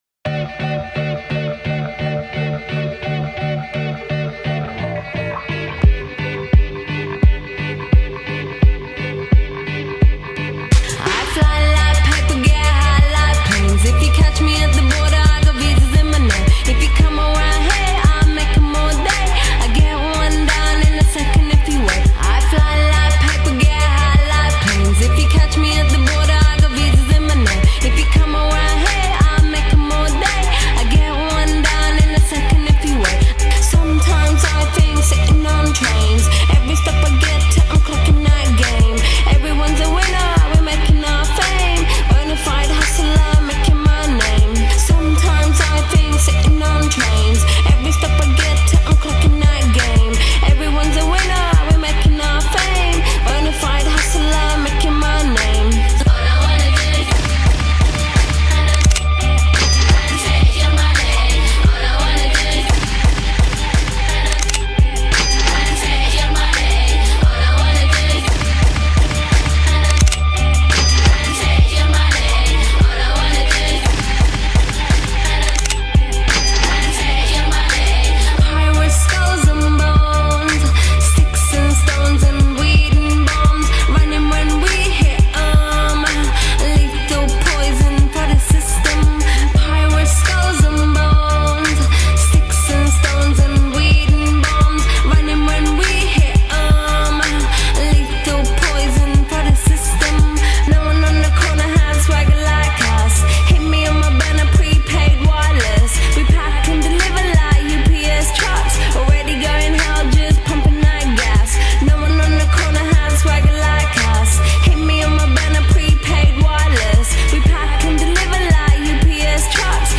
BPM-86-86
Audio QualityPerfect (High Quality)